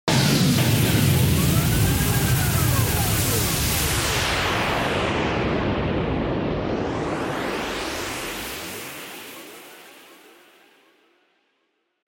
دانلود آهنگ طوفان 26 از افکت صوتی طبیعت و محیط
دانلود صدای طوفان 26 از ساعد نیوز با لینک مستقیم و کیفیت بالا
جلوه های صوتی